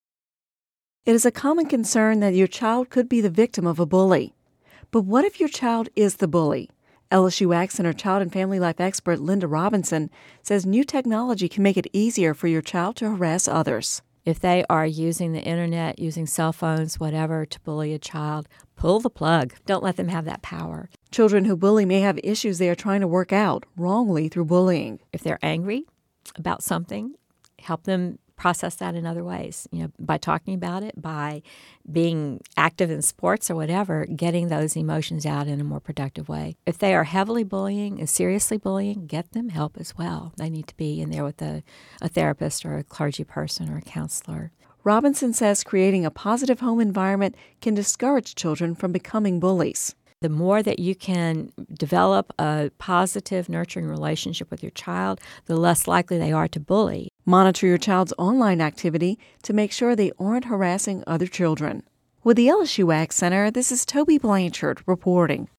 (Radio News 02/07/11) It is a common concern that your child could be the victim of a bully, but what if your child is the bully?